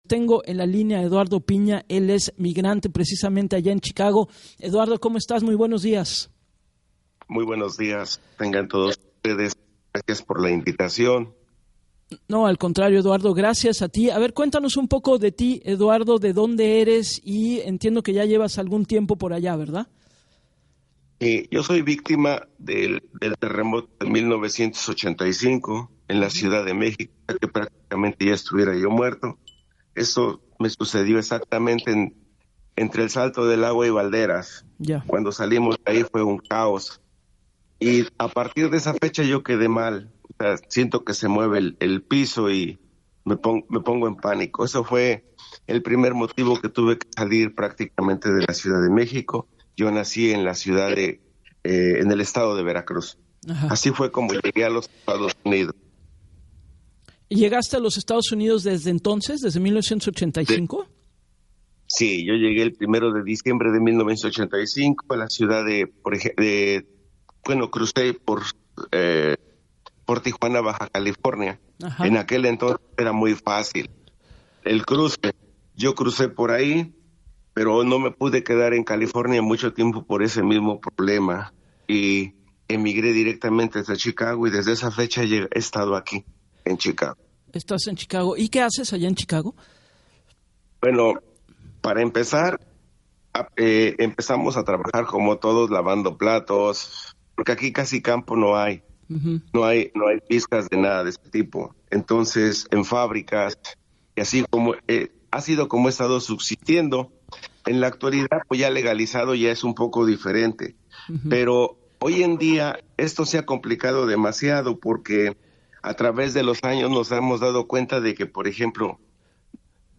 compartió en entrevista para “Así las Cosas” con Gabriela Warkentin